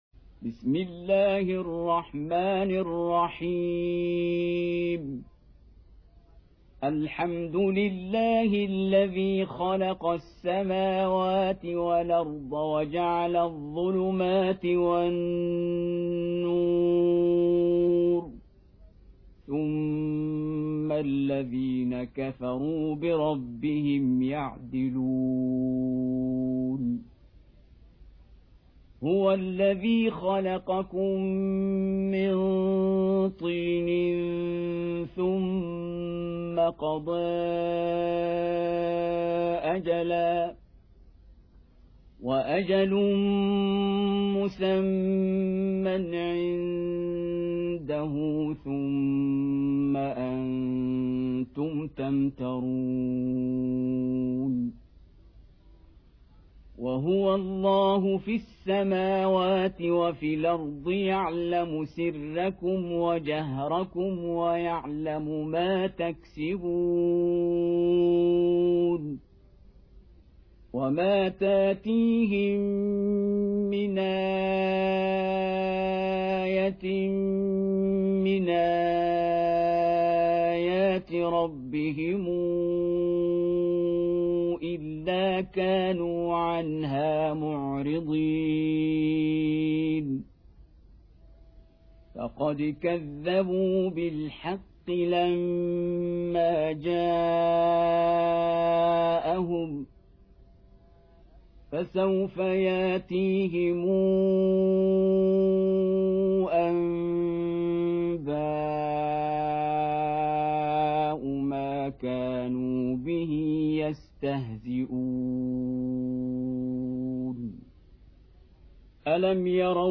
6. Surah Al-An'�m سورة الأنعام Audio Quran Tarteel Recitation
Surah Repeating تكرار السورة Download Surah حمّل السورة Reciting Murattalah Audio for 6.